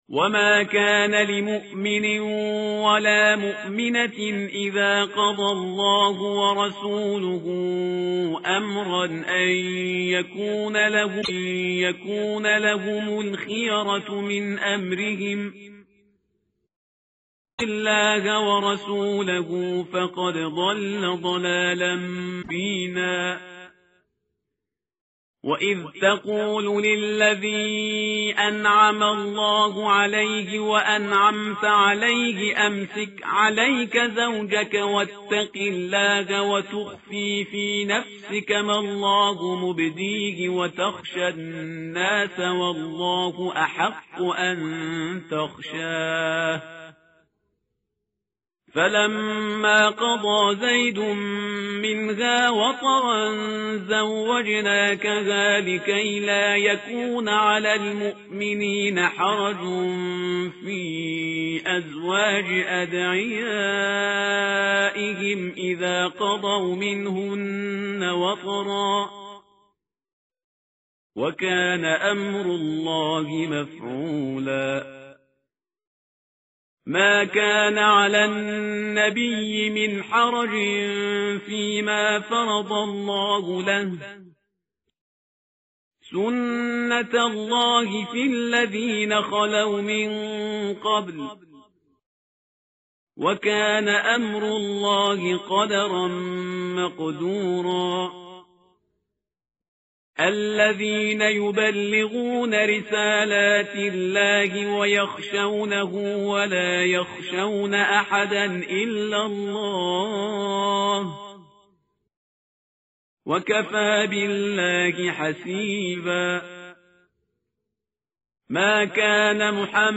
متن قرآن همراه باتلاوت قرآن و ترجمه
tartil_parhizgar_page_423.mp3